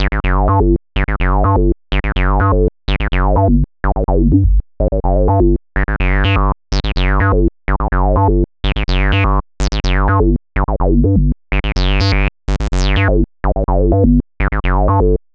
cch_acid_nochorus_125.wav